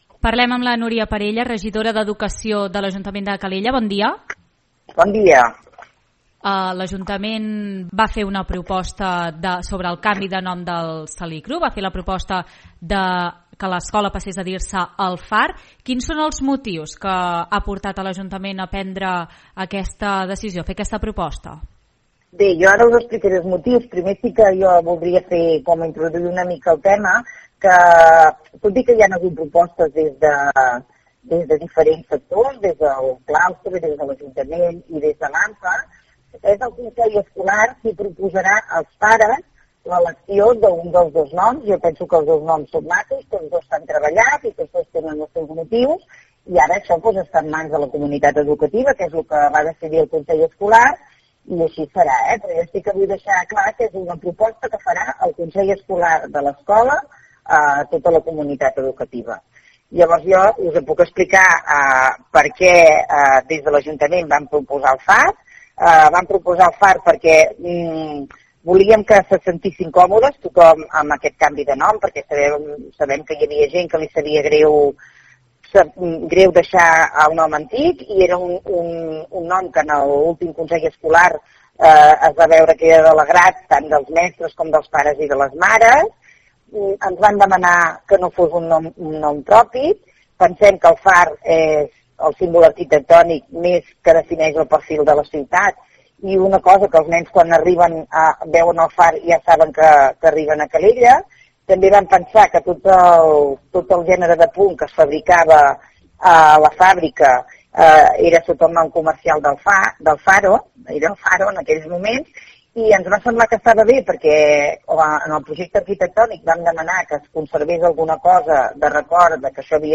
Són declaracions de Núria Parella en una entrevista a l’Info Migdia.
A continuació podeu recuperar l’entrevista íntegra a la tinent d’Alcaldia d’Educació, Núria Parella.